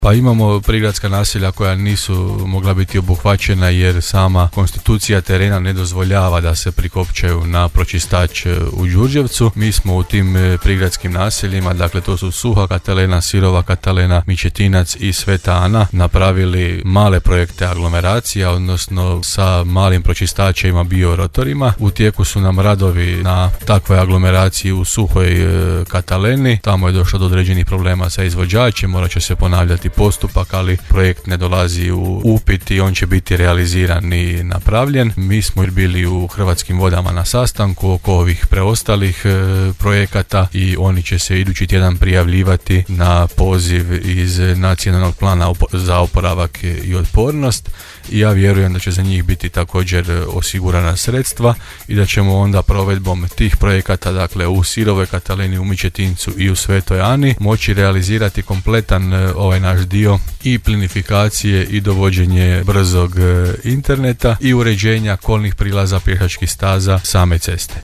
– rekao je između ostalog gradonačelnik Grada Đurđevca Hrvoje Janči u emisiji Gradske teme u programu Podravskog radija i naveo radove na području Grada;